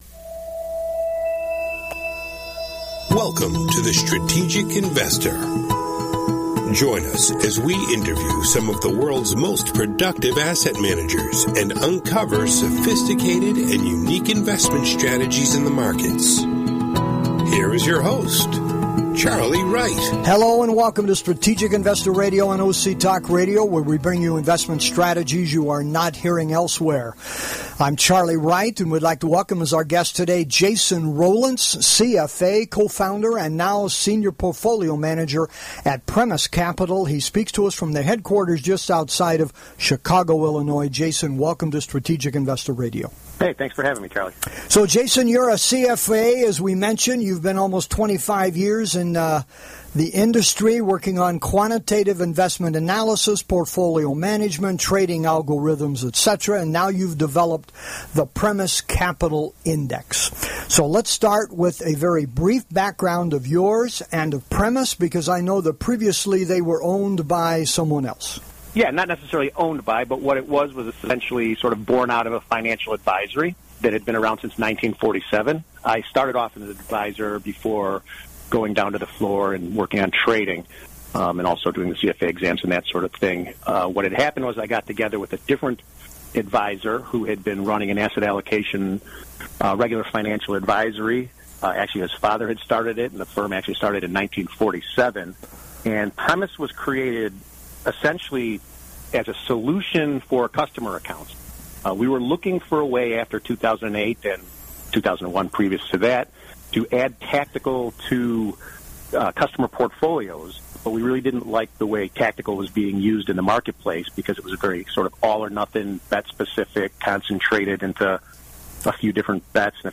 He explains their strategy and how and why it is unique in the world of tactical investing. Listen to this very interesting interview for a unique way to use a tactical strategy to protect a portfolio